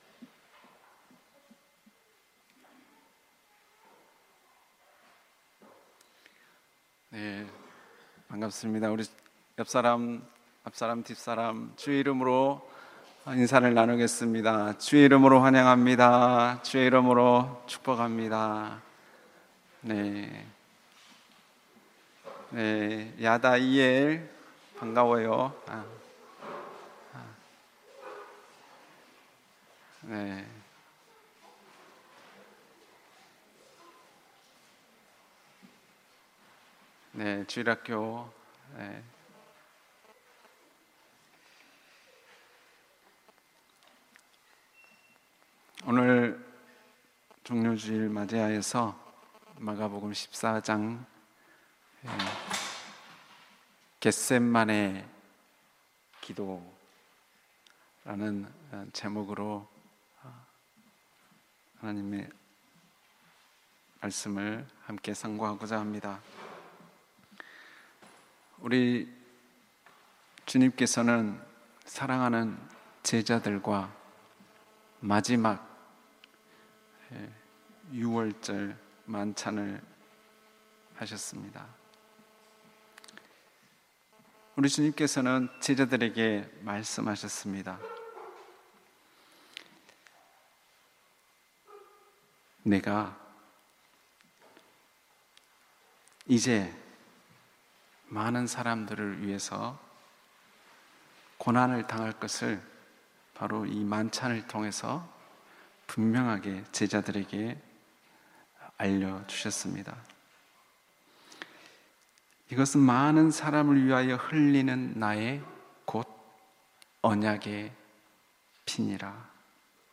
Play버튼을 클릭하시면 주일예배 설교말씀을 들으실 수 있습니다.